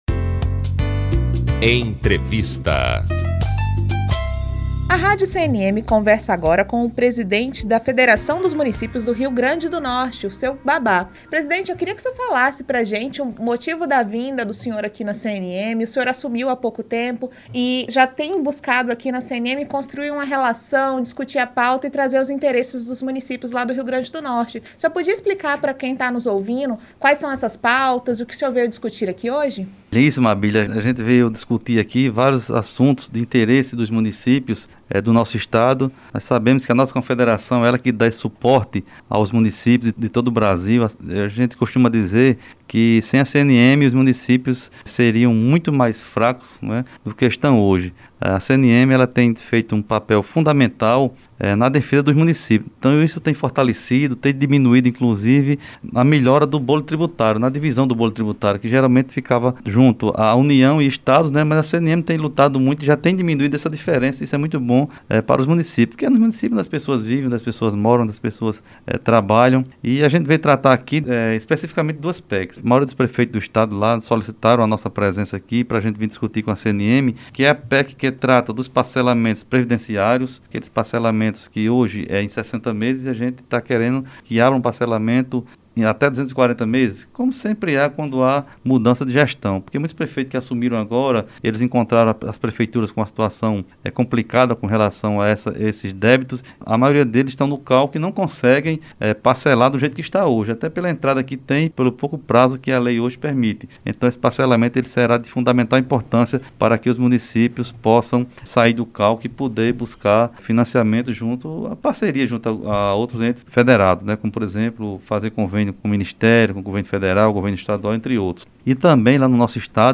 Entrevista - Presidente da Femurn - Anteomar Pereira da Silva
Entrevista---Presidente-da-Femurn-Anteomar-Pereira-da-Silva.mp3